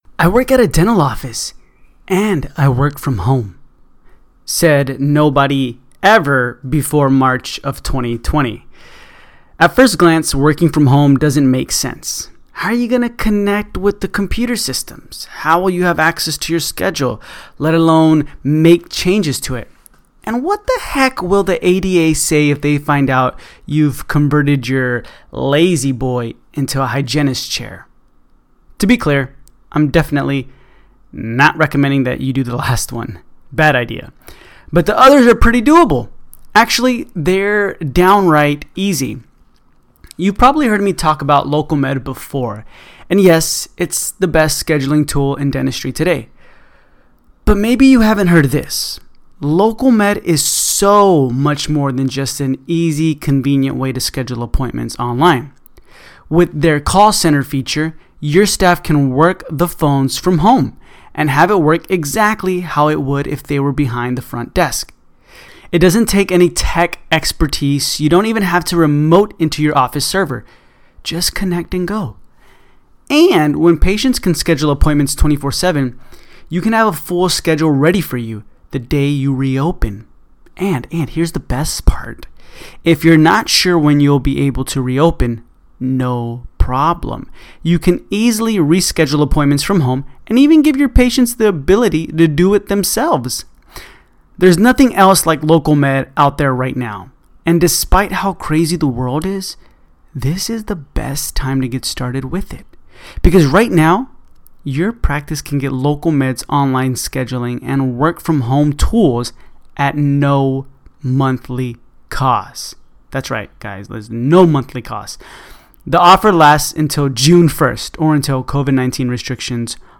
DDMC Lecture